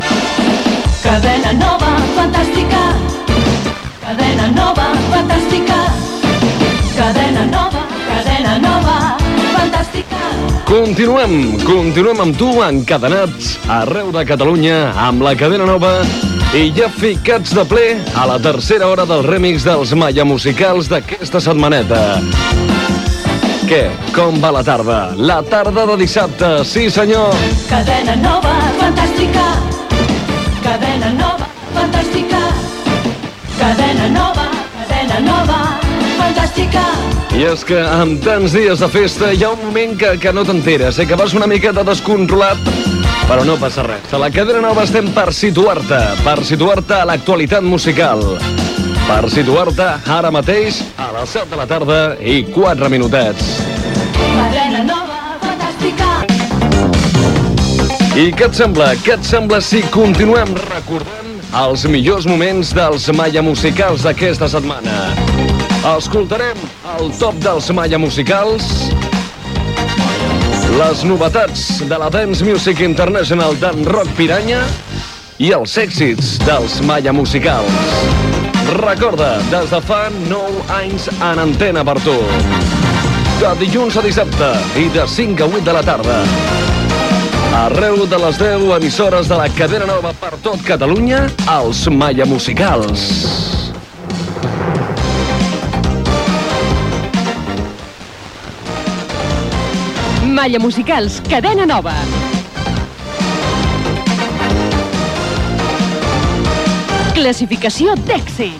Remix del programa de la tarda del dissabte.
Musical
FM